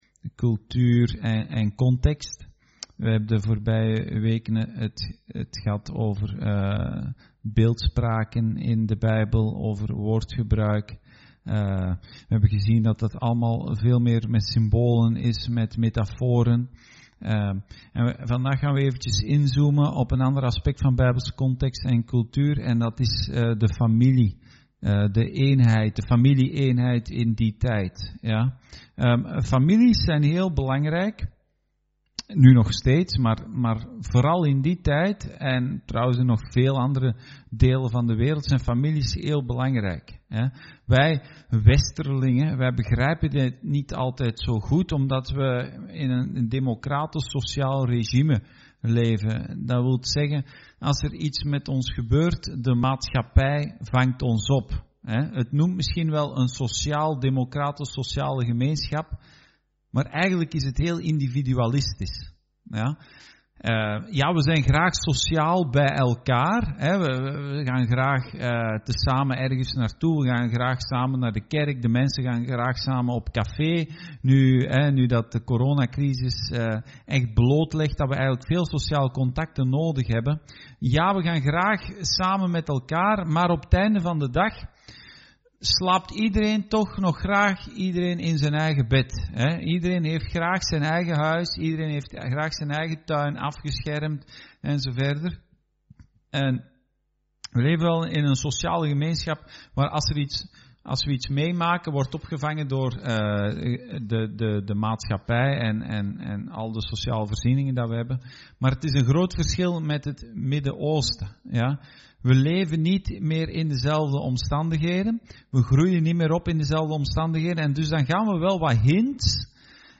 Dienstsoort: Bijbelstudie